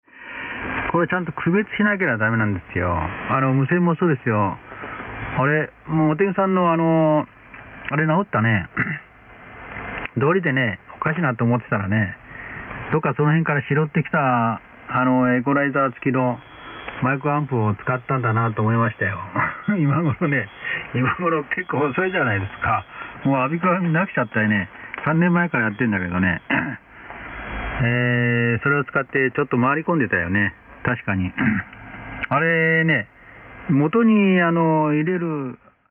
Sample Hi‑Fi SSB Audio
Rx:FT-DX 9000D Rx band width 3.4kHz